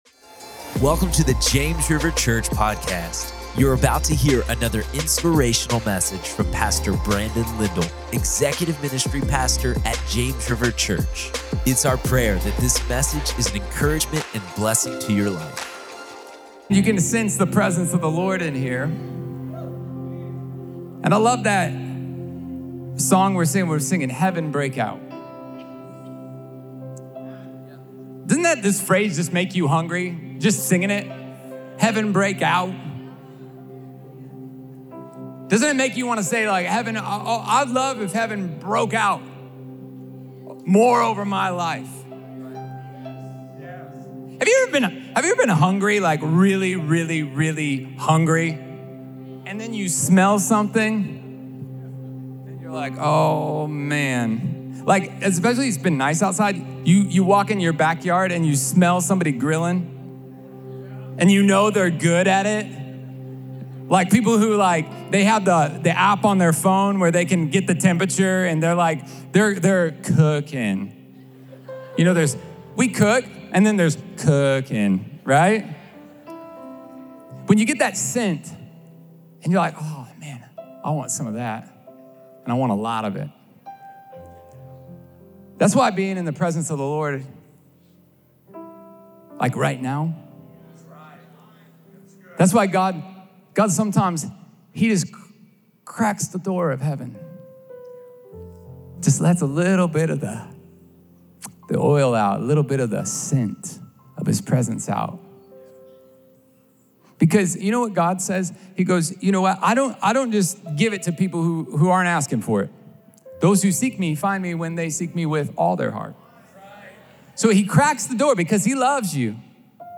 Prayer
Sermon Description